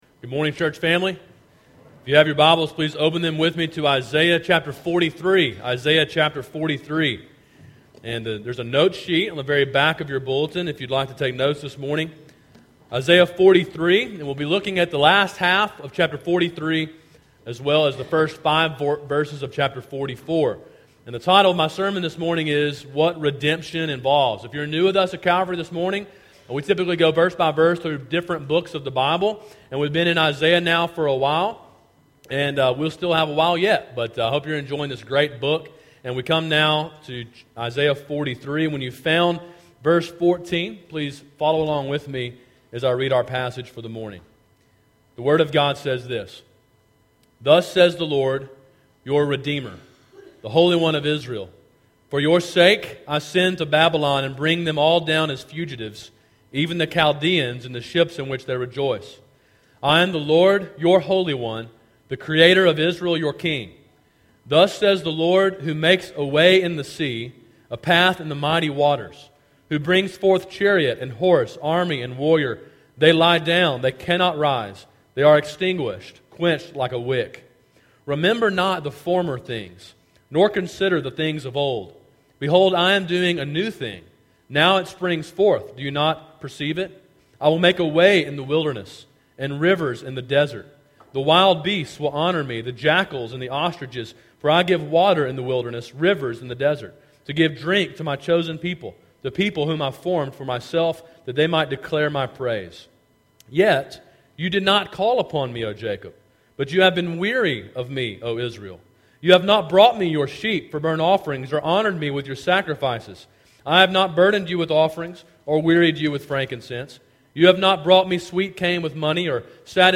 Sermon: “What Redemption Involves” (Isaiah 43:14 – 44:5)